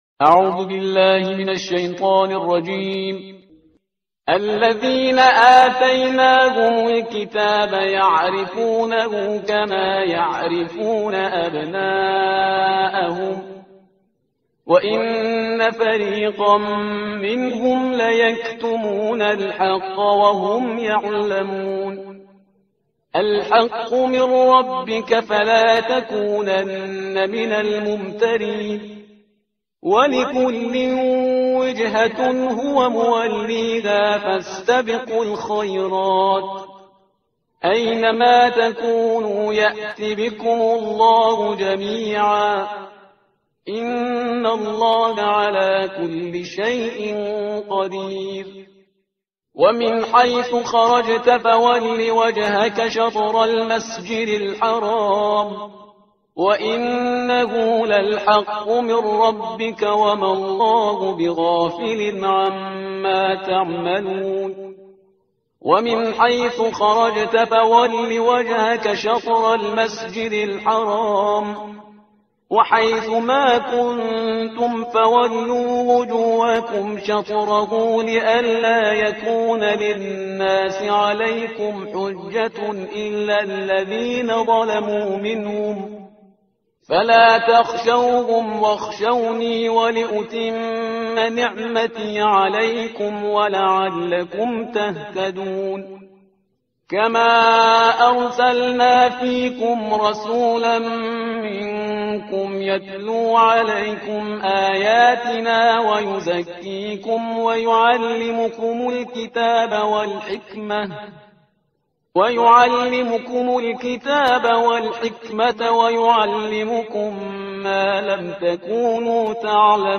ترتیل صفحه 23 قرآن – جزء دوم سوره بقره